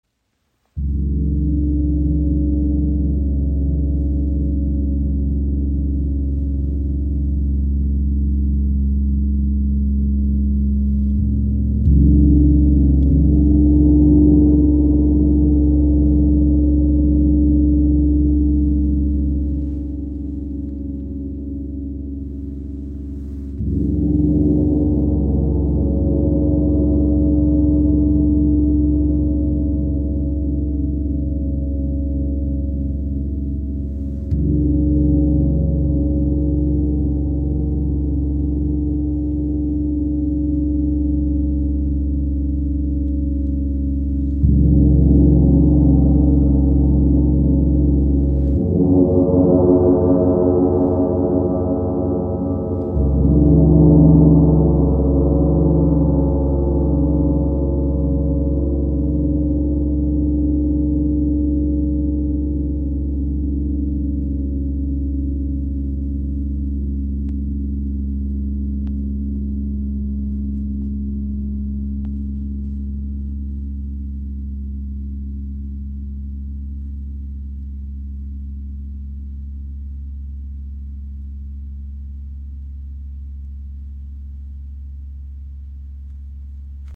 Klangbeispiel
Sein Klang ist tief, ausgewogen und reich an Obertönen, die eine Atmosphäre zwischen Erdentiefe und kosmischer Weite erschaffen. Mit Reibungsschlägeln wie den B Love Flumies entstehen sanfte, traumhafte Wal- und Delfinsounds.
WOM KI Gong – Der Klang der inneren Weite | ø 90 cm | Edelstahl-Gong Wie aus der Tiefe des Meeres geboren, singt dieser Gong in Tönen von Walgesang und innerer Weite.